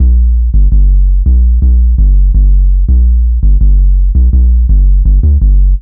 Index of /90_sSampleCDs/Zero-G - Total Drum Bass/Instruments - 1/track06 (Bassloops)